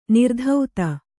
♪ nirdhauta